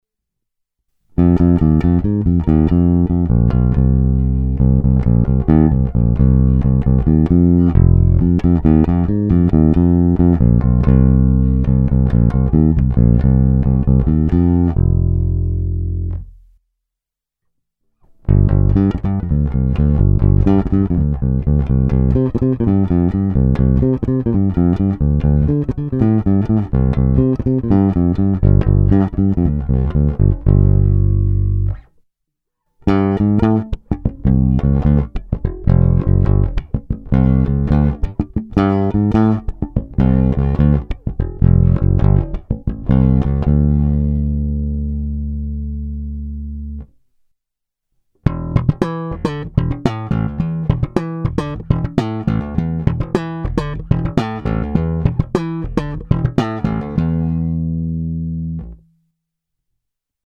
Bručivá, agresívnější, skvěle použitelná i na slapovou techniku.
Není-li uvedeno jinak, všechny nahrávky byly provedeny rovnou do zvukové karty a dále kromě normalizace ponechány bez úprav.
Bonusová ukázka včetně slapu